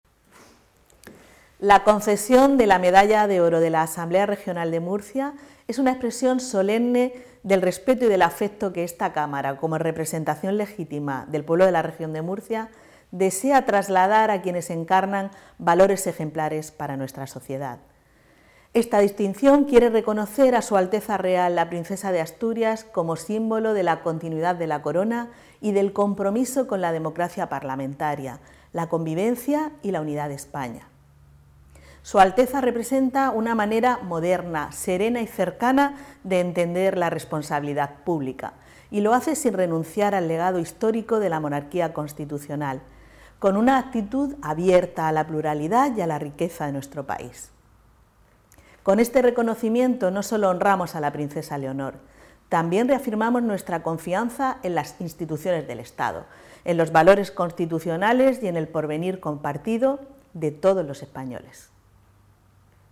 • Audio - Declaraciones de la presidenta de la Asamblea Regional, Visitación Martínez, sobre la concesión de la Medalla de Oro de la Cámara Parlamentaria a la Princesa de Asturias